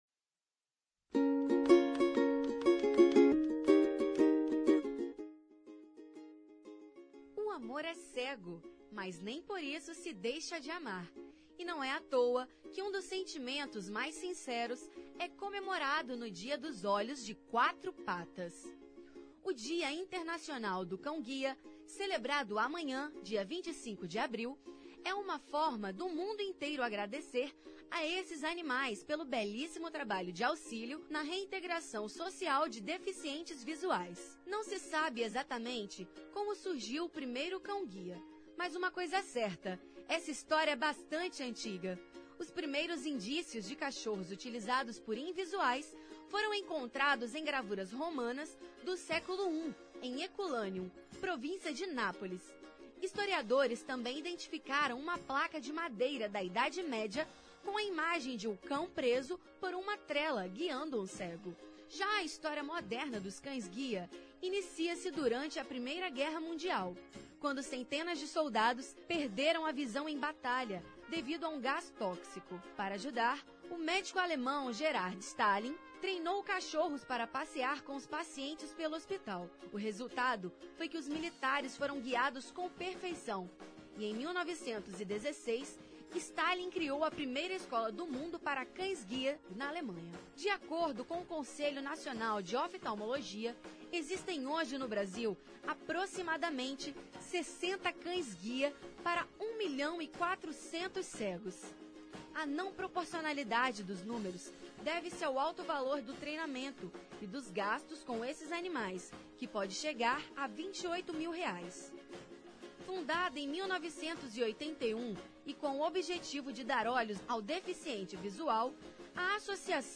Confira a entrevista: Cão-Guia Download : Cão-Guia